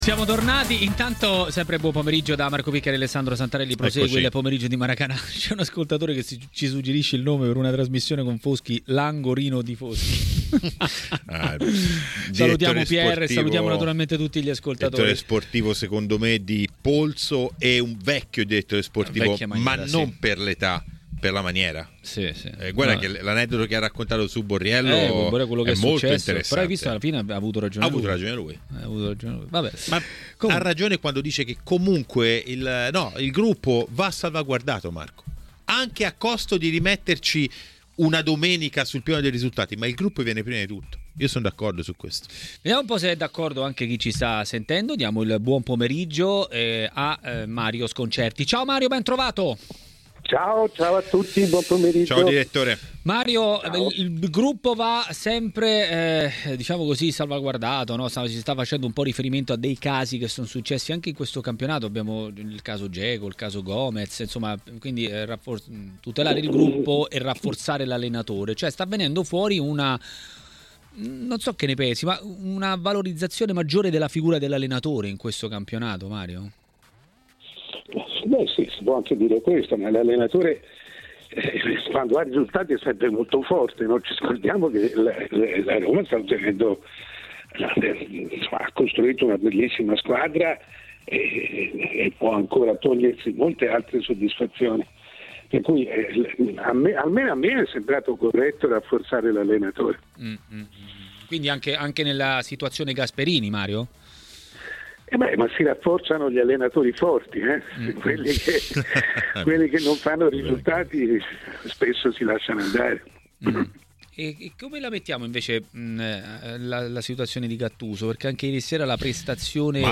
A TMW Radio, durante Maracanà, è arrivato il momento del direttore Mario Sconcerti.